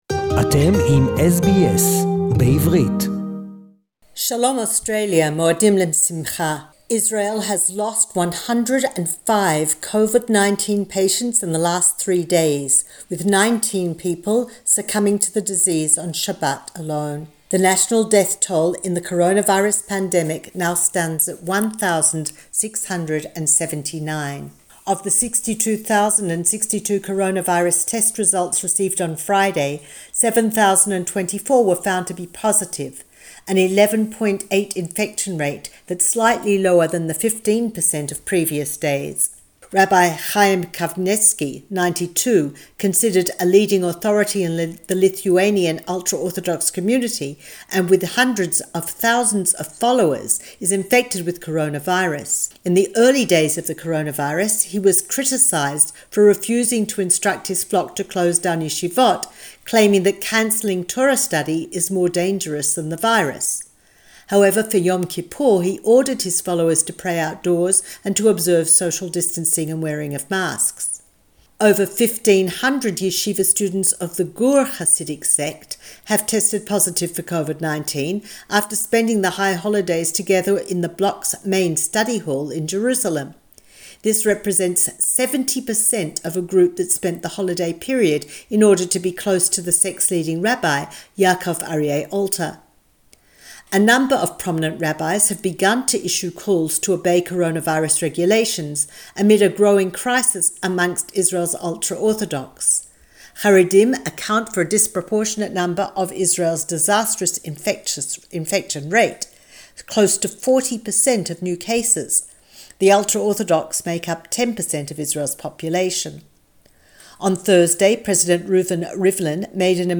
Defying protest ban, 1000's demonstrate against Netanyahu, Jerusalem report in English 4.10.2020